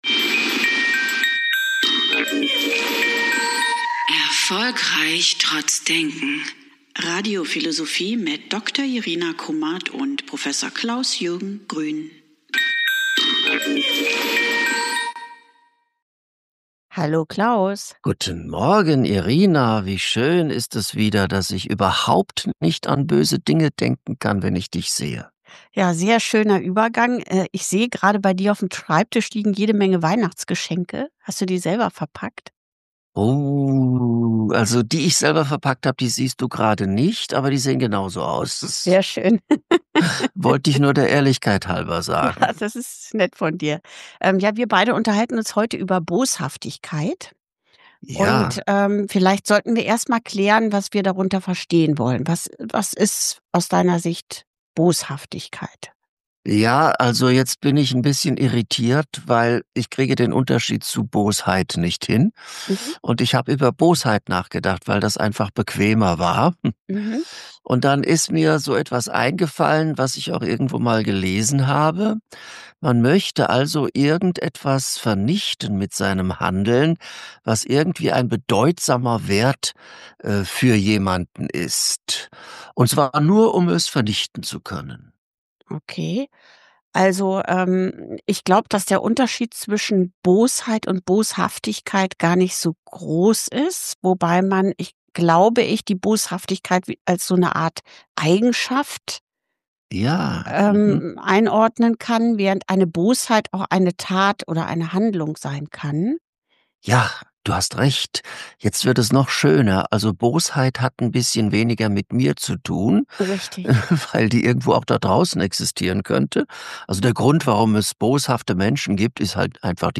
im Gespräch über Boshaftigkeit.